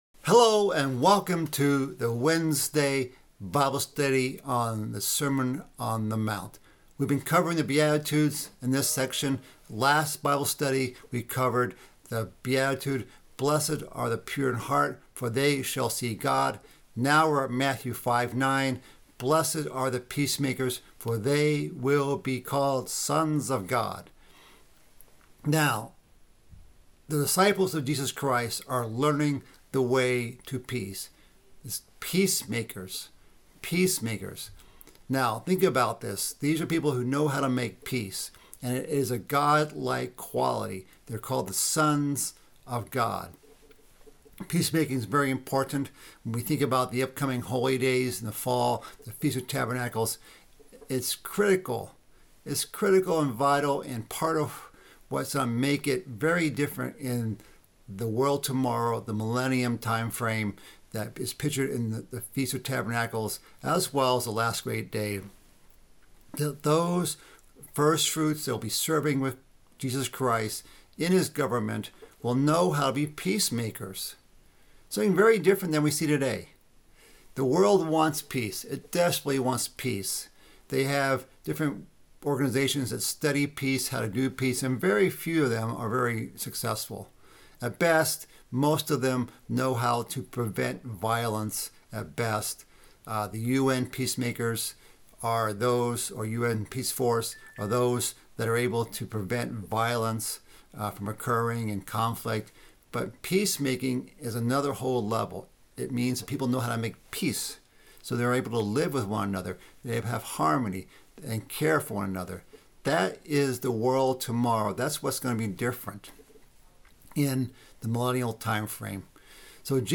Mid-week Bible study finishing up the beatitudes. Blessed are the peacemakers and those who are persecuted for righteousness' sake.